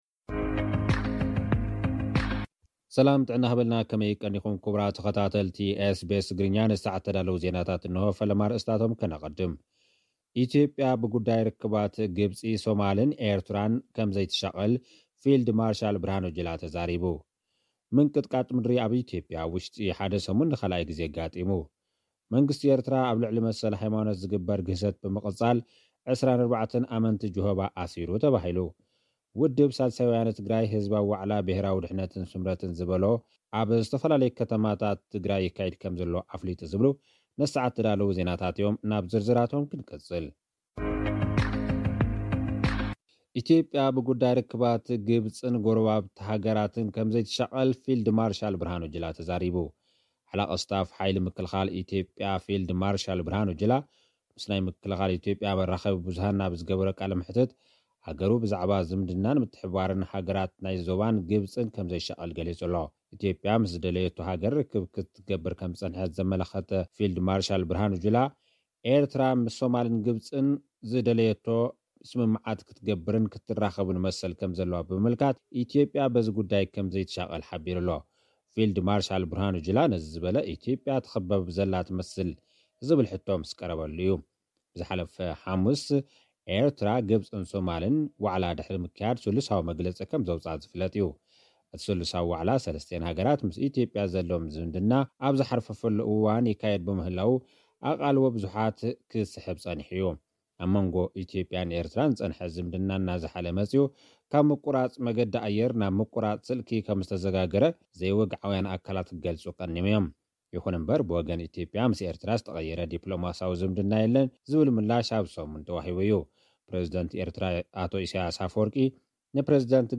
ኢትዮጵያ ብጉዳይ ግብጽን ጎረባብታን ከም ዘይትሻቐል ገሊጻ። (ካብ ጸብጻብ)